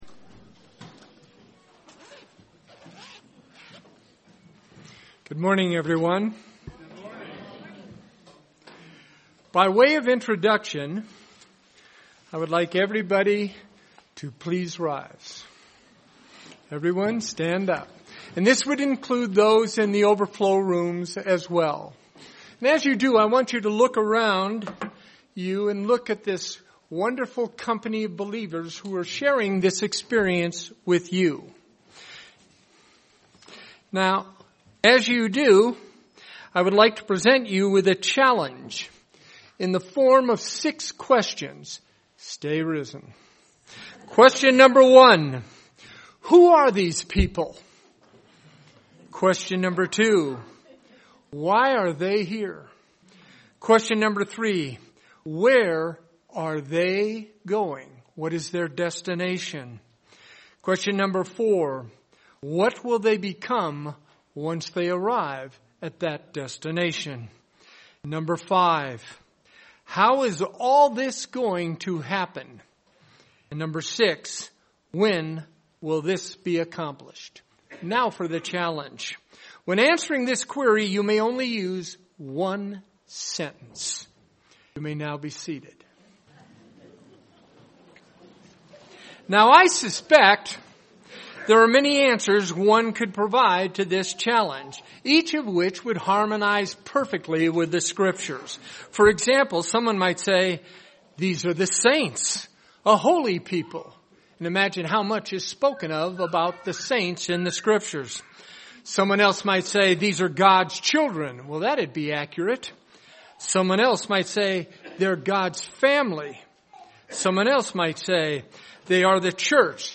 This sermon was given at the Bigfork, Montana 2016 Feast site.